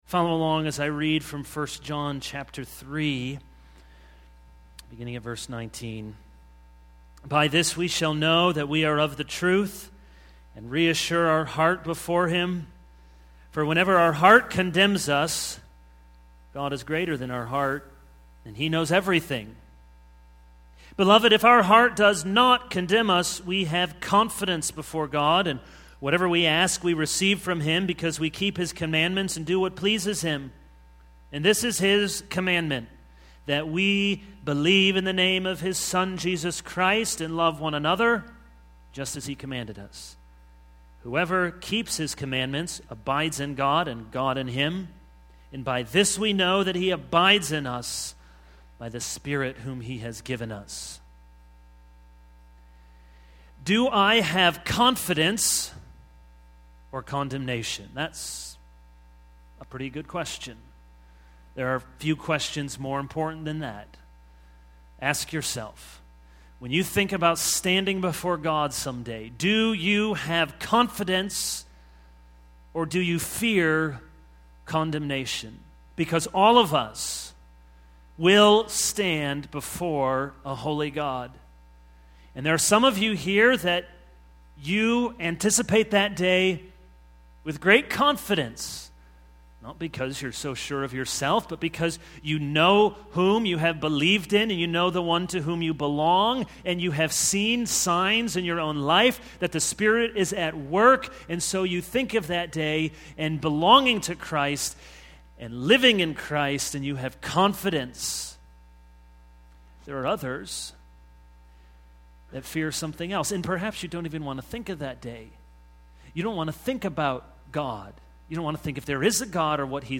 This is a sermon on 1 John 3:19-24.